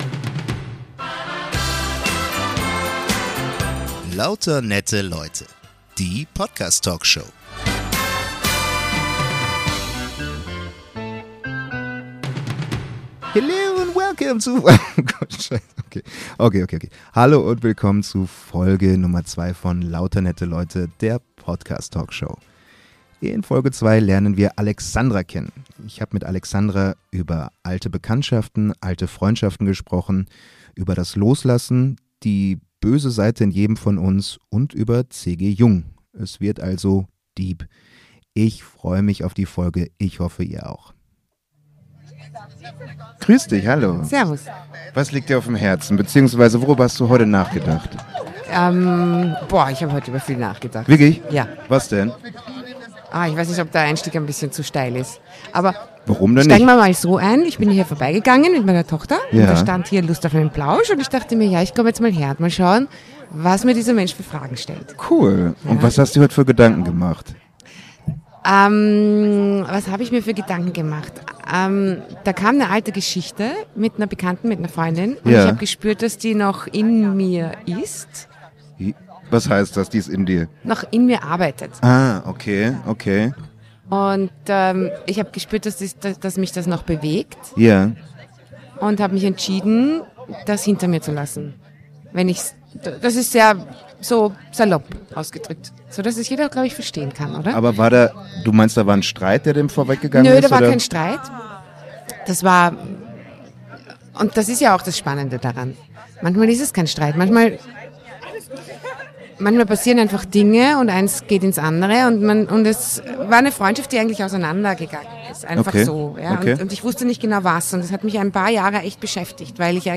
am Rande eines kleinen Fests